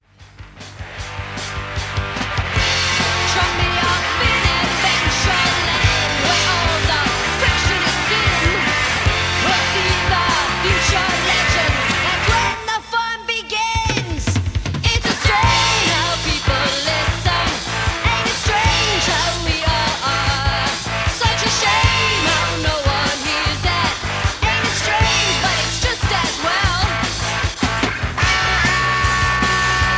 Lead Vocals
Lead Guitar
Rhythm Guitar & Vocals
Bass Guitar & Vocals
Drums
Keyboards, Vocals & Engineer